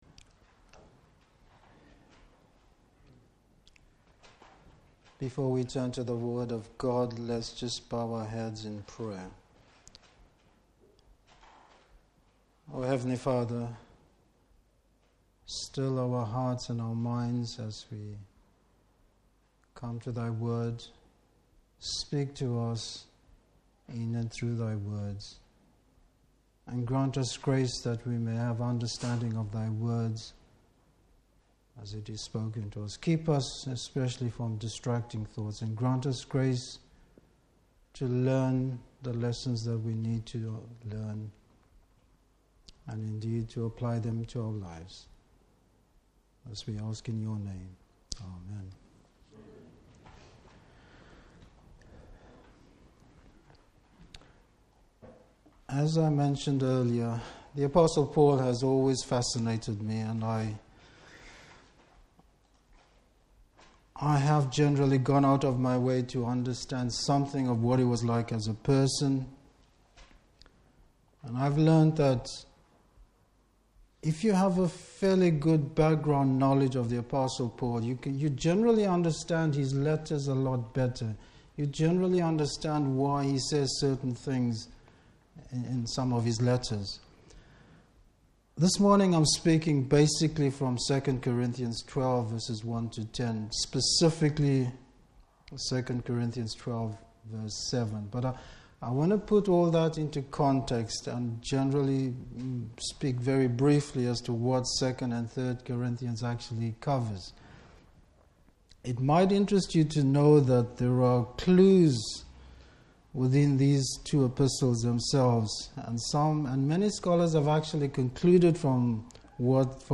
2 Corinthians 12:1-10 Service Type: Morning Service Bible Text